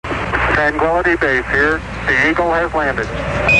6.nasa landing
nasa_landing.mp3